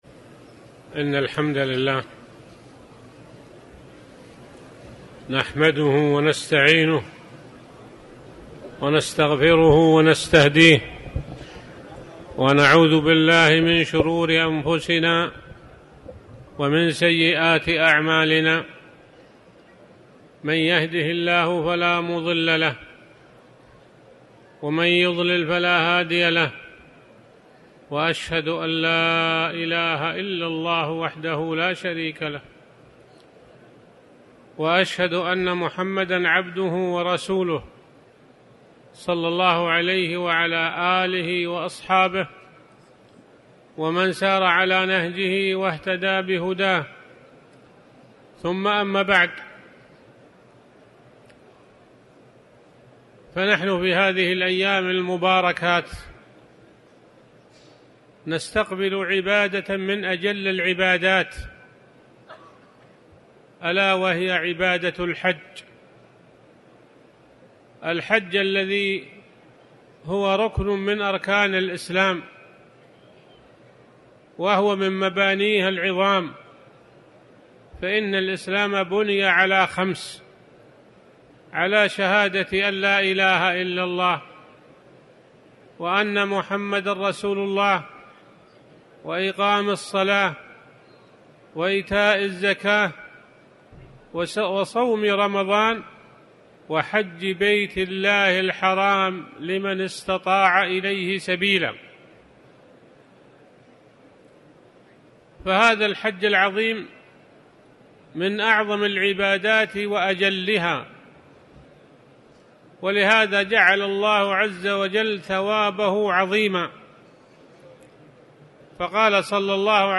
تاريخ النشر ١٩ ذو القعدة ١٤٤٠ هـ المكان: المسجد الحرام الشيخ
19dhw-alqadh-mhadrhaltwhyd-wmnask-alhj.mp3